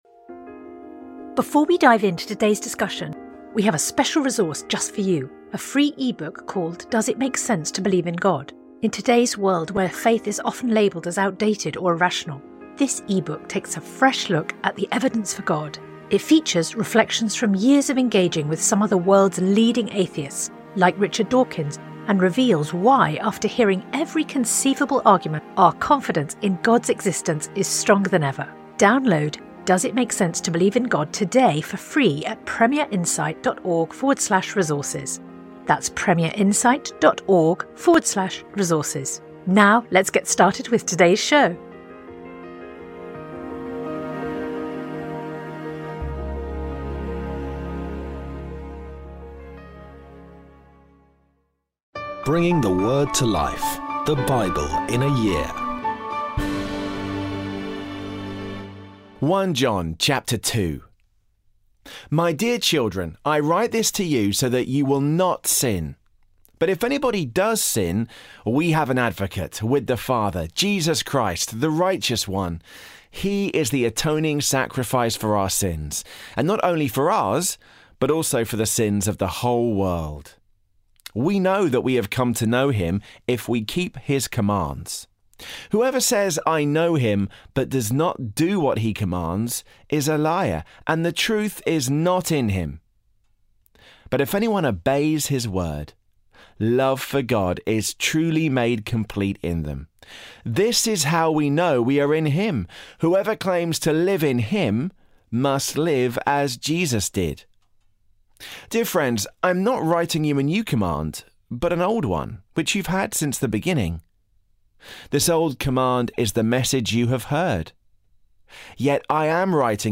Today's readings come from 1 John 2; Leviticus 19-21